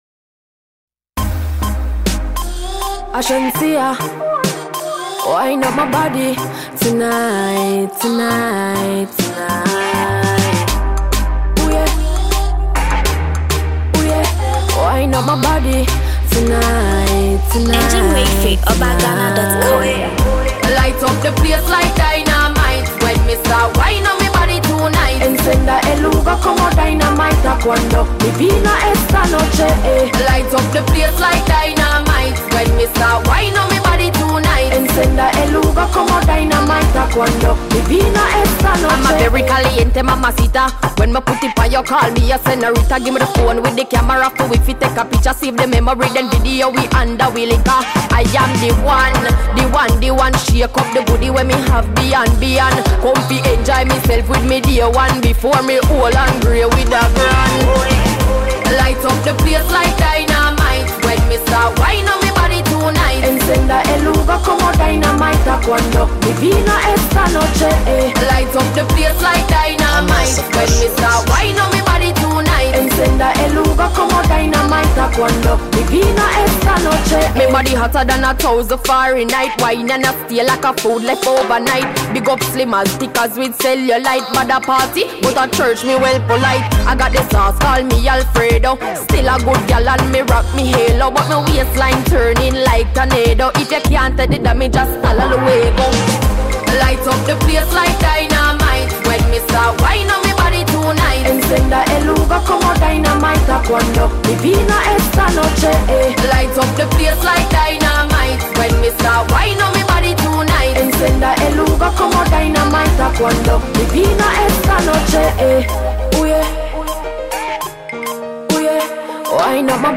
Reggae/Dancehall
Jamaican dancehall goddess